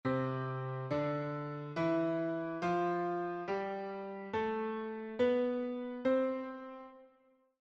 Here it is written in treble clef and bass clef below, along with a recording of this C major scale played on the piano.
C major scale in treble clef and bass clef
piano_C_major_scale.mp3